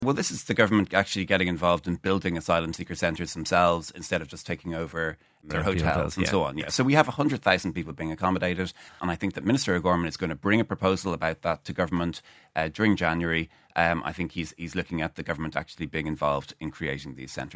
Minister of State Ossian Smyth says the Government has been scrambling to find spaces.